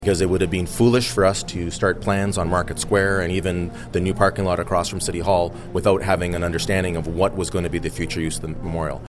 Regarding the city hall downtown area, Mayor Panciuk told Quinte News, following his speech that the key thing is making a decision about Memorial Arena.